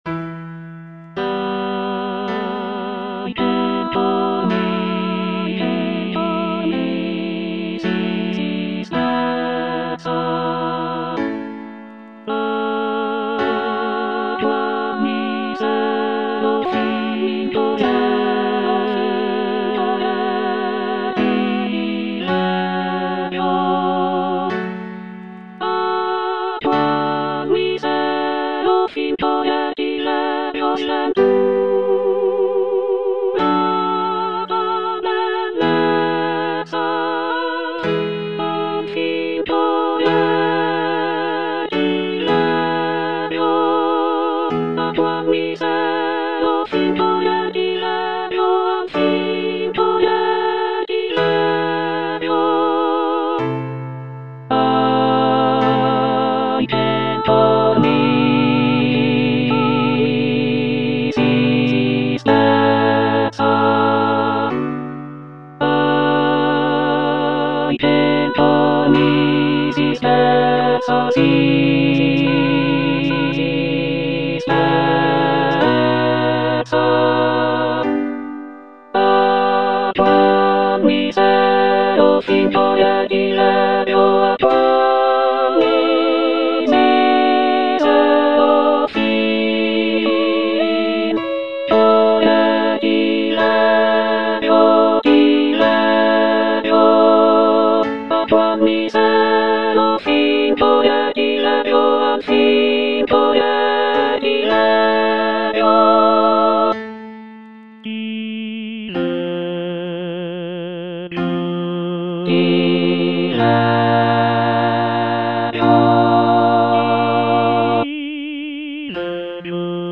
C. MONTEVERDI - LAMENTO D'ARIANNA (VERSION 2) Coro II: Ahi! che'l cor mi si spezza - Alto (Emphasised voice and other voices) Ads stop: auto-stop
It is a deeply emotional lament aria that showcases the singer's ability to convey intense feelings of grief and despair.
The music is characterized by its expressive melodies and poignant harmonies, making it a powerful and moving example of early Baroque vocal music.